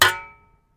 default_shovel_steel2.ogg